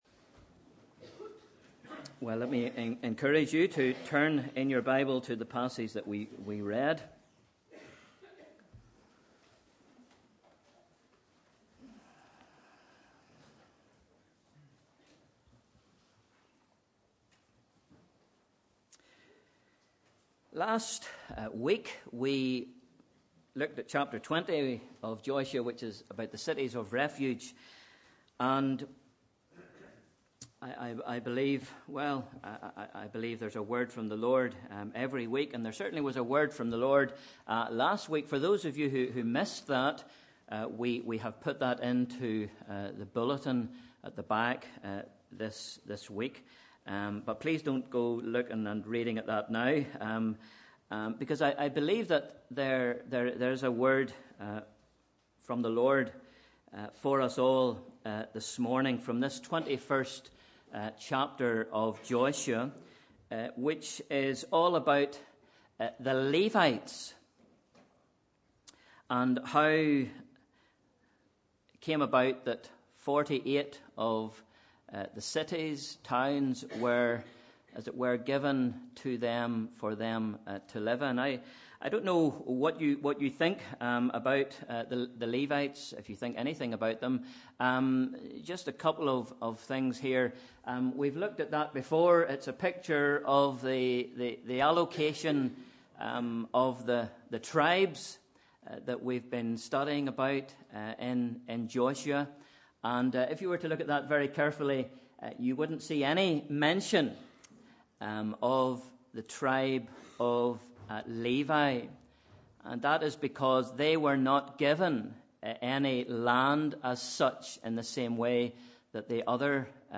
Sunday 26th February 2017 – Morning Service